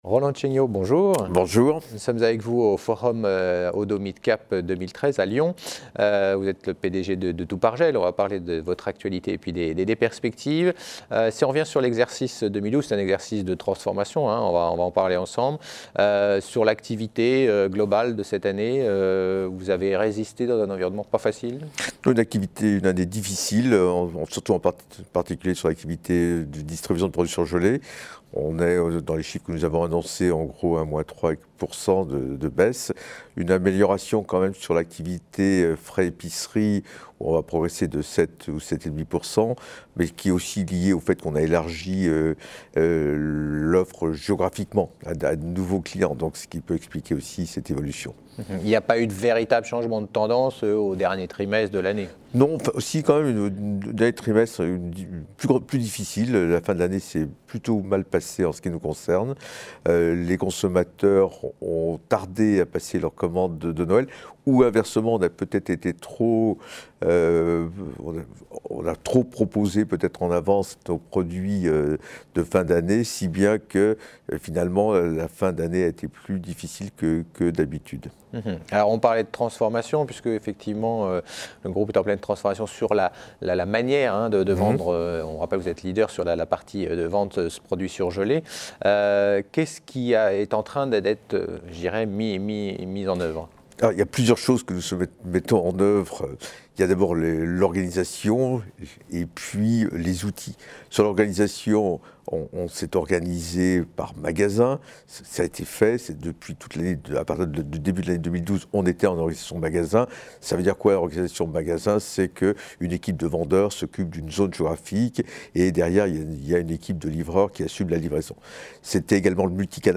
Oddo Midcap Forum 2013 : Stratégie du spécialiste de la vente à domicile de produits alimentaires aux particuliers